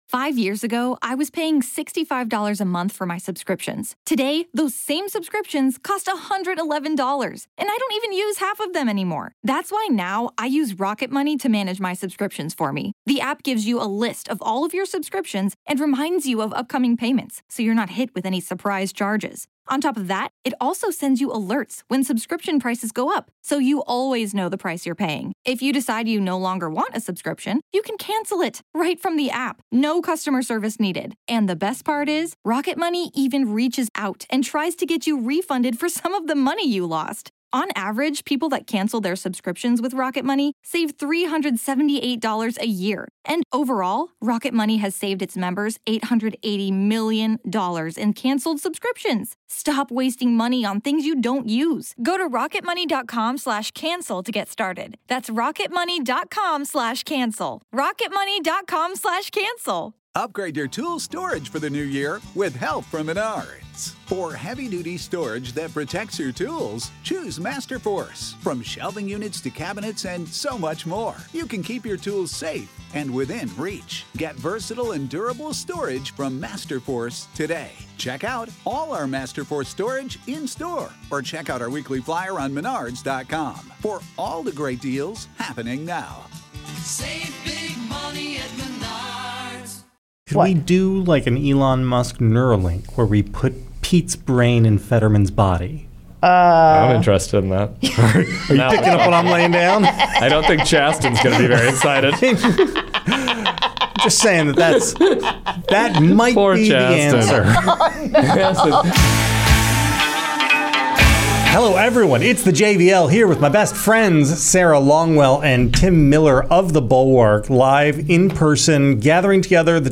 The gang's all in person this week!